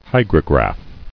[hy·gro·graph]